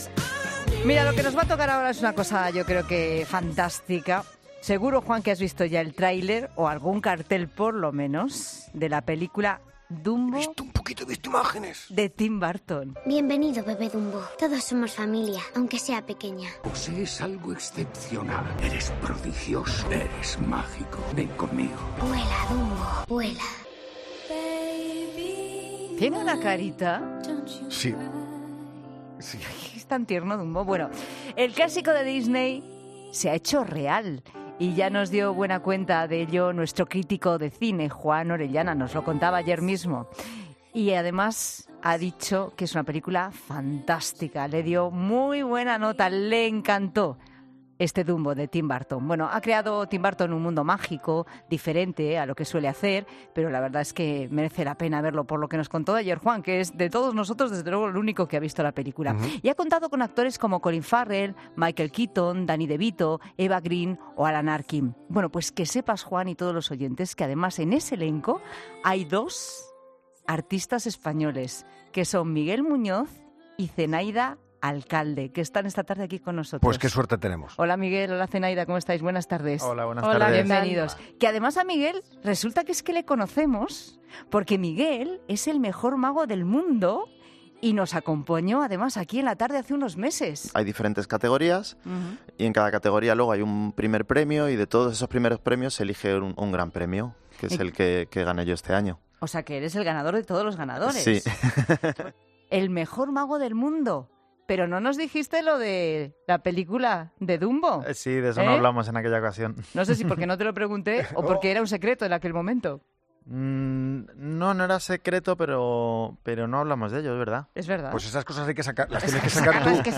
Los dos actores españoles de 'Dumbo'